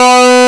MINIMOOG LD.wav